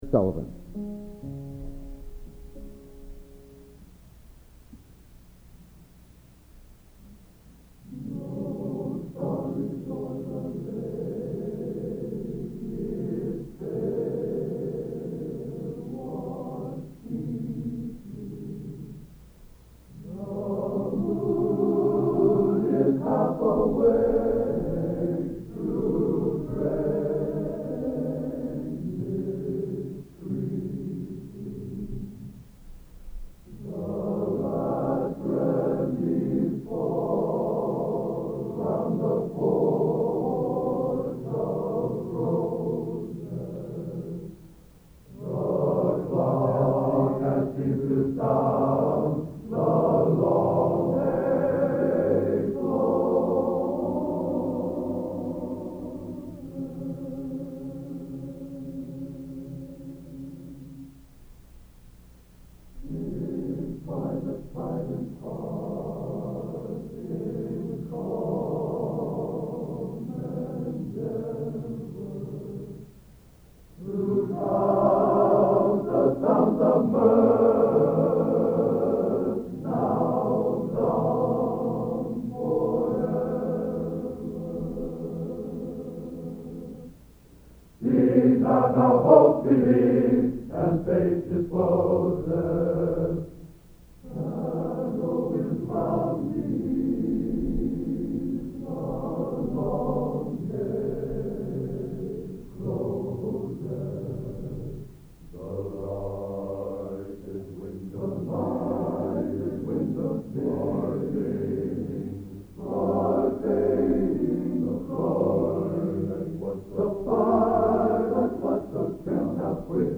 Collection: End of Season, 1979
Location: West Lafayette, Indiana
Genre: | Type: End of Season